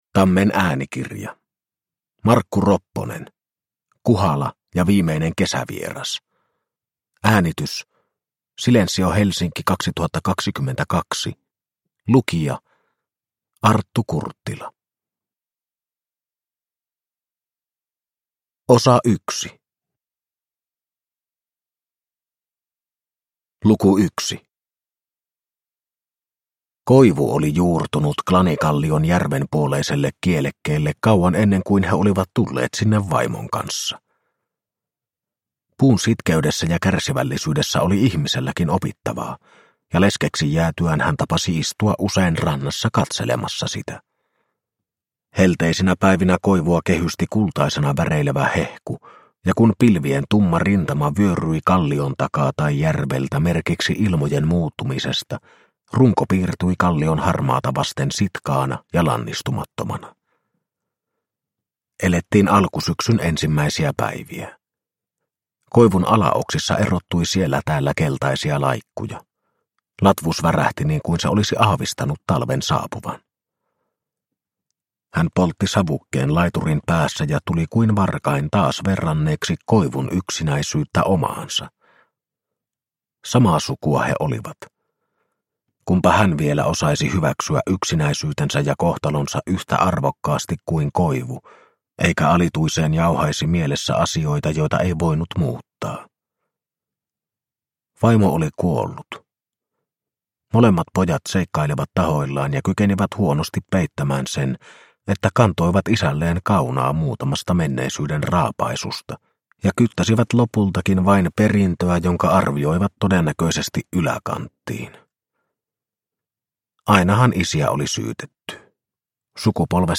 Kuhala ja viimeinen kesävieras – Ljudbok – Laddas ner